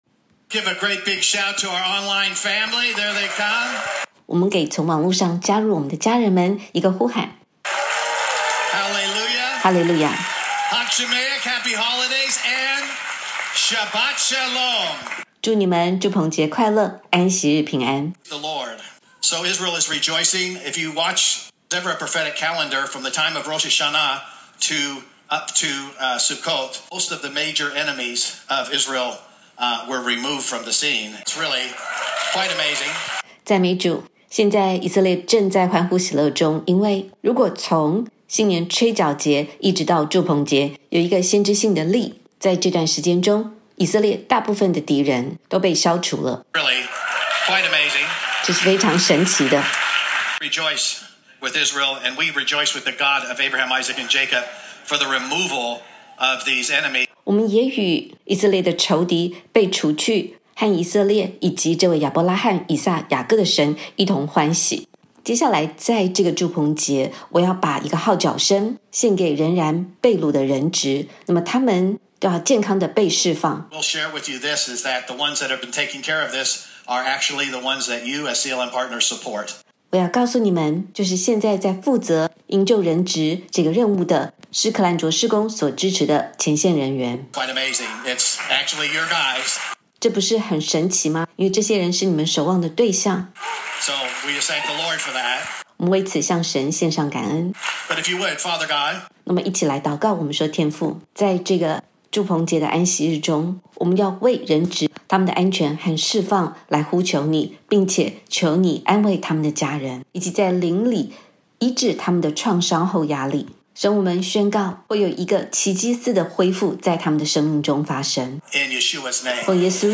先知性行动：用箭击打地七次的第六次 本次语音信息的内容是在住棚节七天节期之间的安息聚会，也是神指示我们在秋天的节期时要用箭击打地七次的第六次。